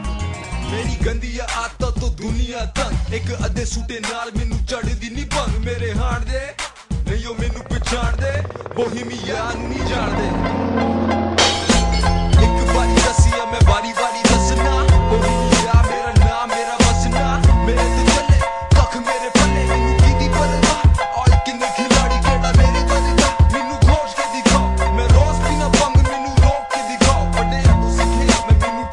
Punjabi Ringtones